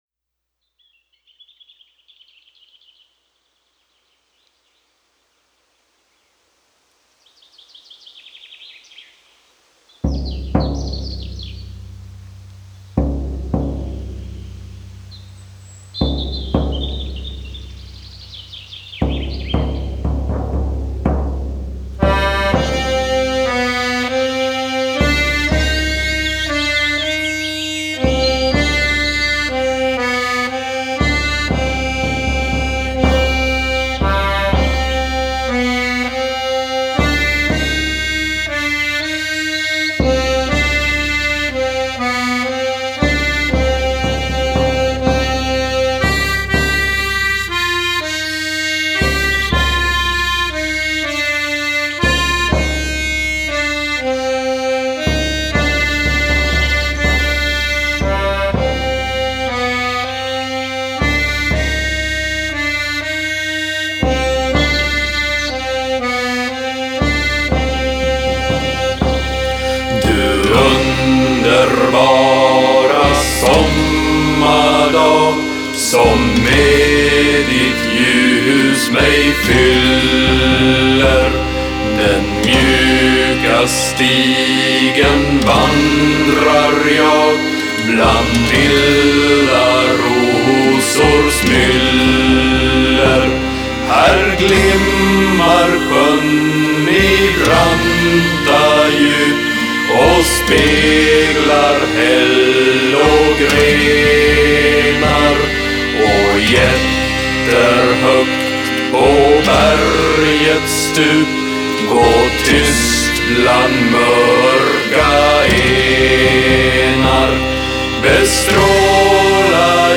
Style: Neofolk